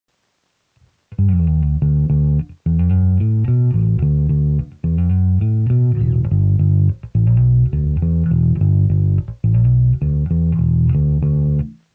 Ok, this was the Ampeg model....completely dry and flat , compression only.....fingerpicked, which I suck at even more than with a pick.....like i said, not much justice......